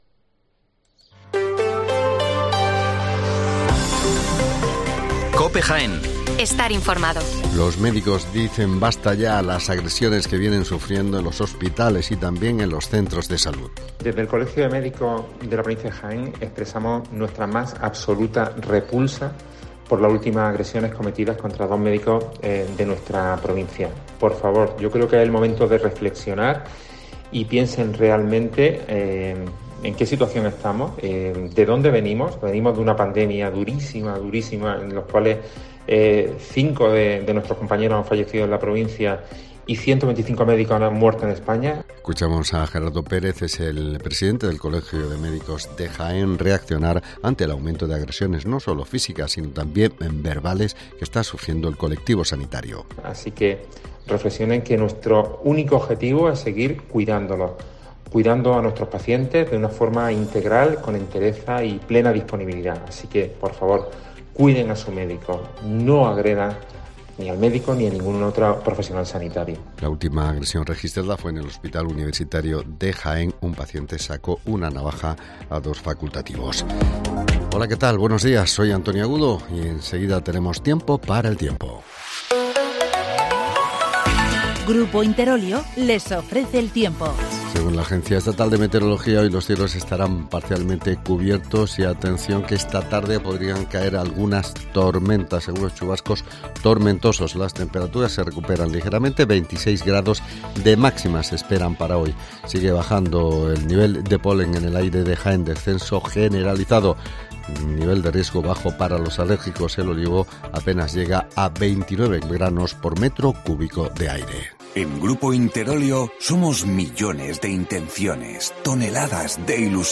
Las noticias locales de las 8'24 del 1 de junio